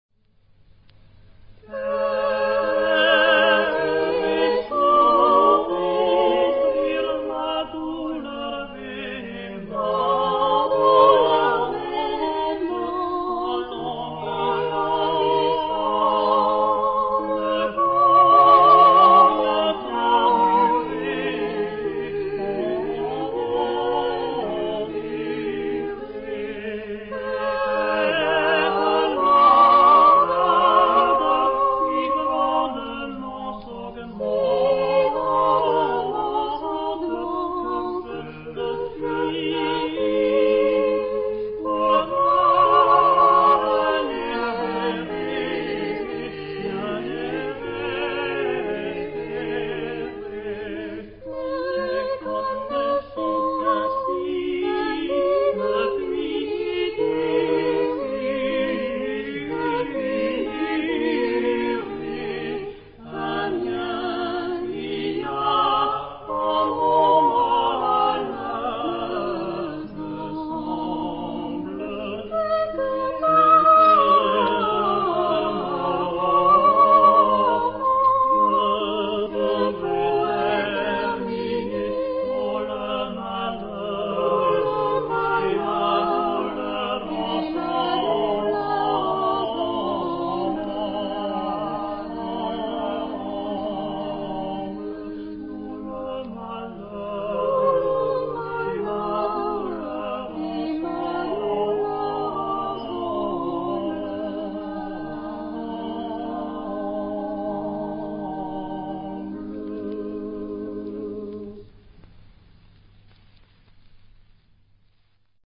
Epoque: 16th century
Genre-Style-Form: Renaissance ; Partsong ; Secular
Type of Choir: SATB  (4 mixed voices )
Tonality: C major